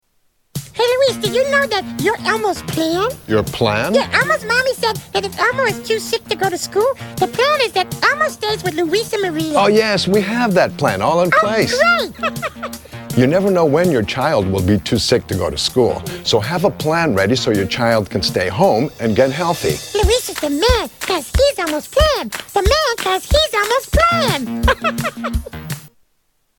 Tags: Media Flu PSA's Flu Public Service Announcements H1N1